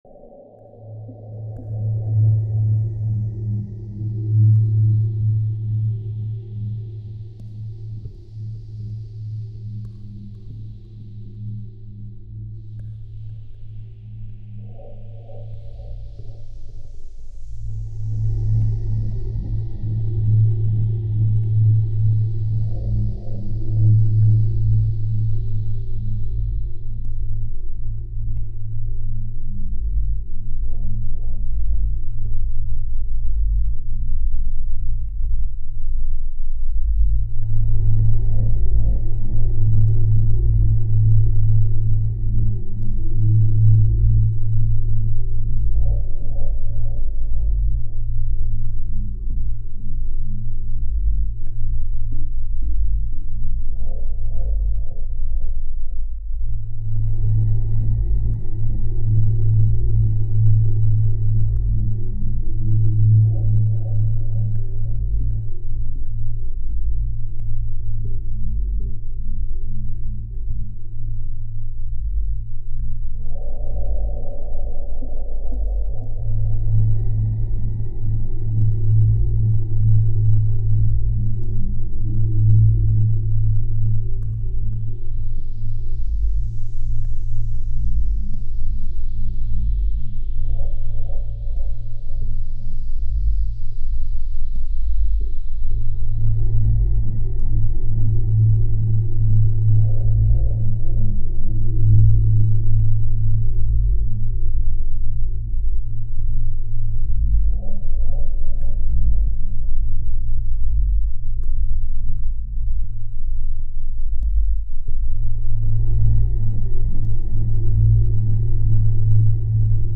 Meditation Music